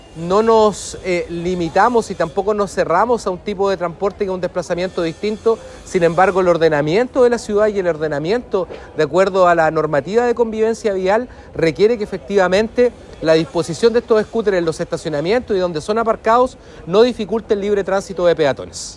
Consultado el seremi de Transportes, Patricio Fierro, reconoció que se han registrado algunas complejidades en relación a la convivencia vial. Además, señaló que muchas veces los usuarios estacionan estos scooter en lugares no apropiados y que además transitan por lugares que no son los aptos.
cuna-scooter-patricio-fierro.mp3